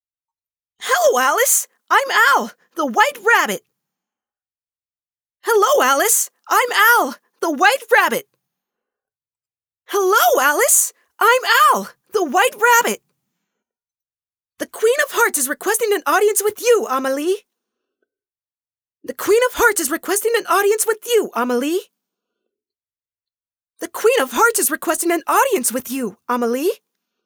Voice Actor
Voice: High-pitched, but must still be slightly masculine.